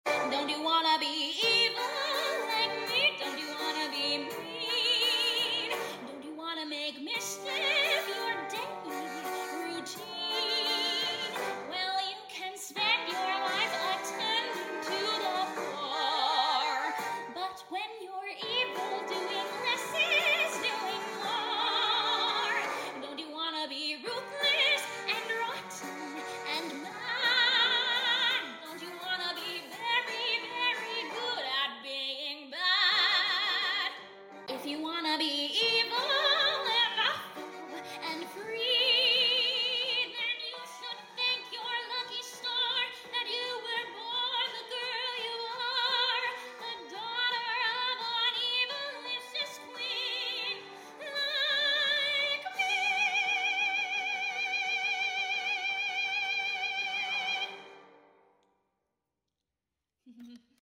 a soprano villain song